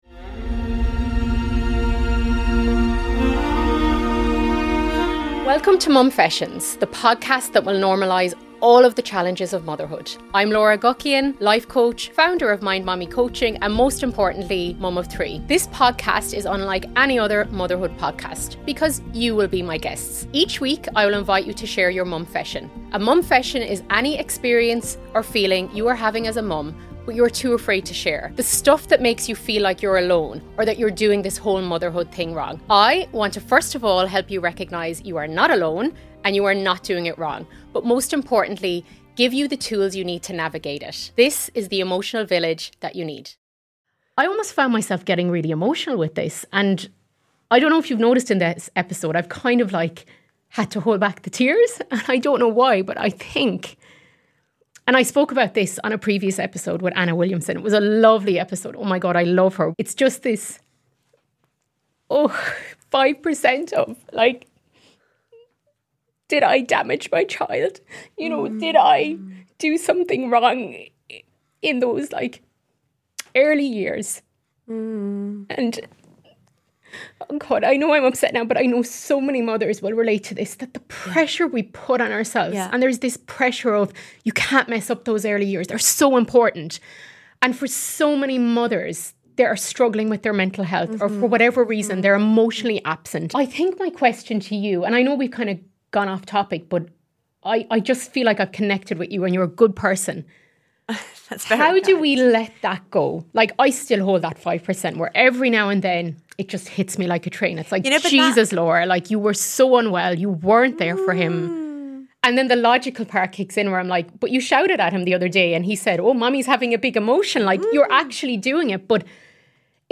I had to submit a short highlight reel to showcase what Momfessions is about .
I picked the most raw and vulnerable clips that sound nothing like most parenting podcasts.